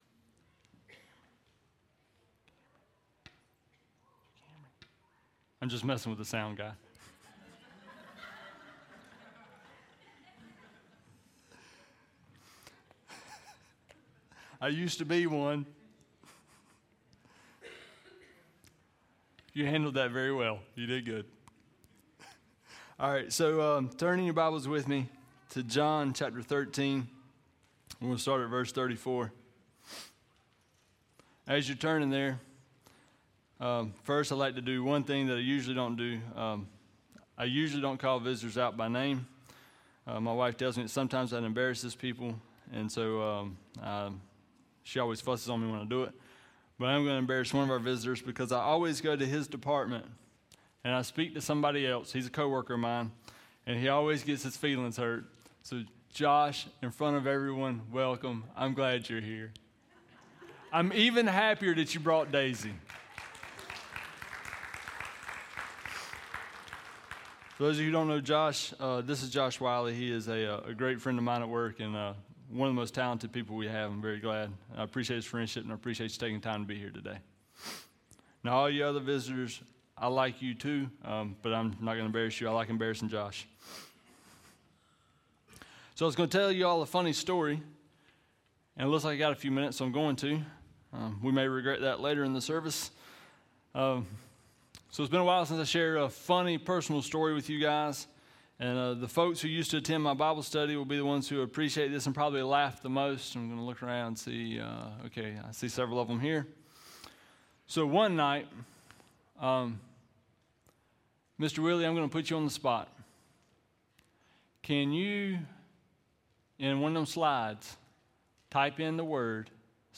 Sermons | First Assembly of God Rock Hill